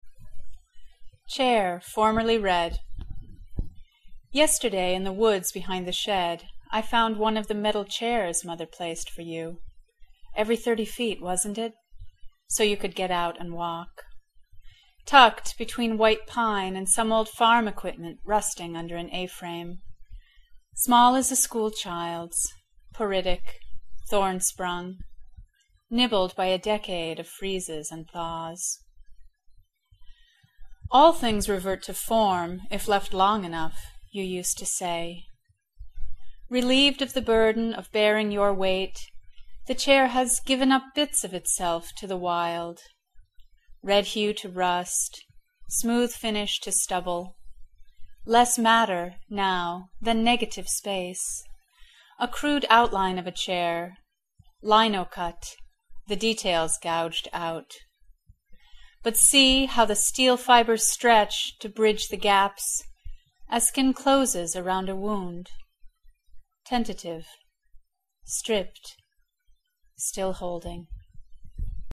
And a voice like a bell.